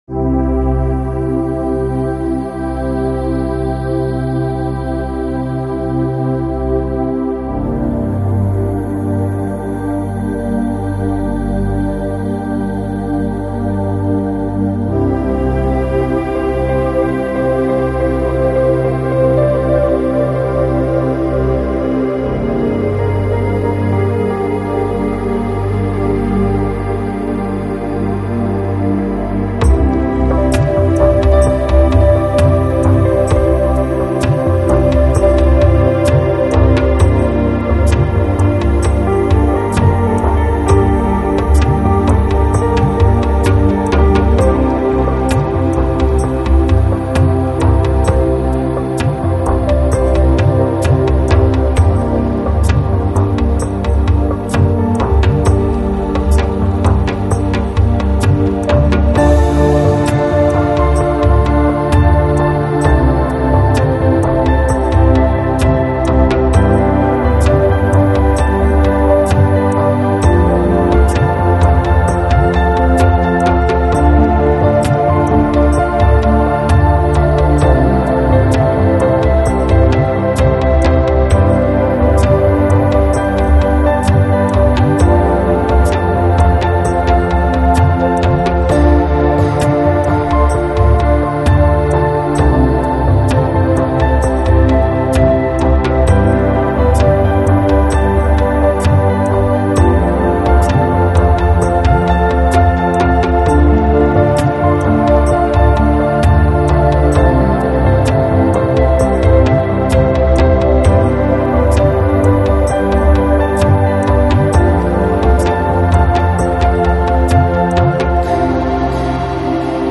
New Age, Lounge